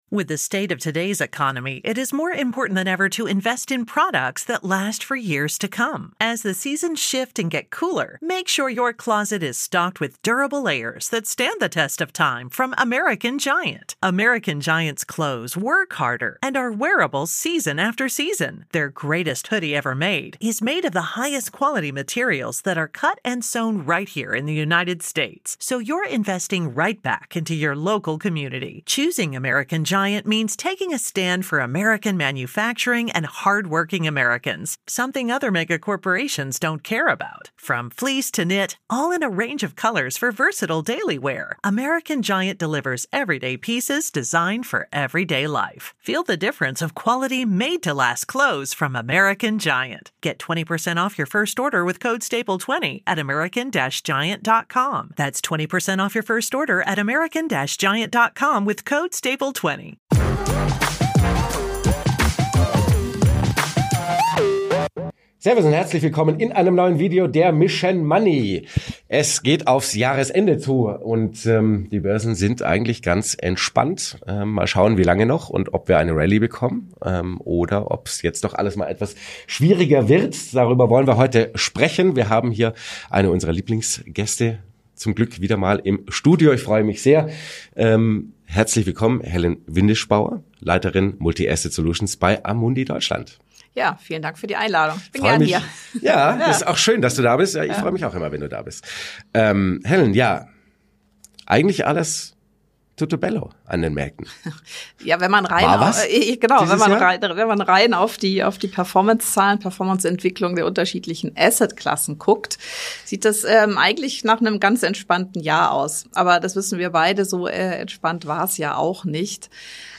Den besten Schutz bieten eine breite Diversifikation und die Beimischung von Unternehmen, die unabhängig vom Zoll-Streit gute Perspektiven bieten. Etwa in Deutschland, wo von den Ausgaben für Infrastruktur und Rüstung kleinere Unternehmen profitieren sollten. Welche Assets die Strategin jetzt spannend findet, verrät sie im Interview.